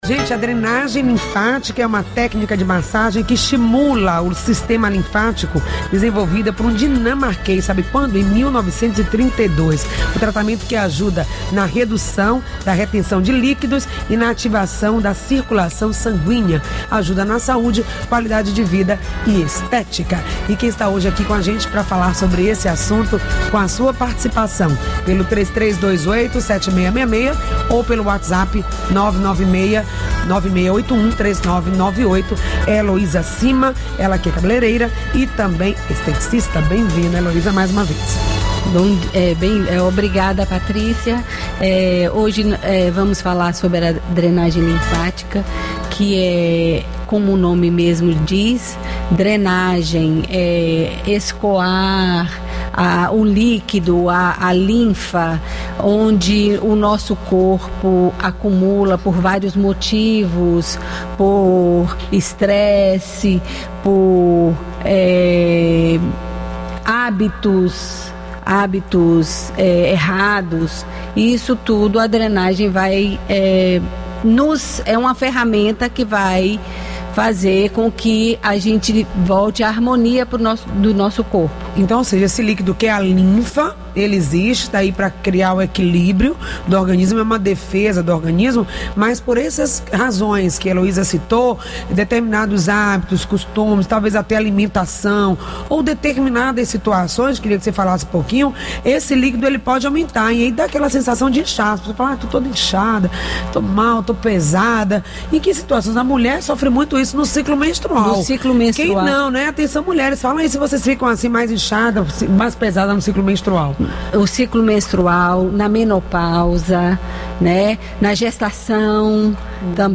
Em entrevista ao programa Saúde no ar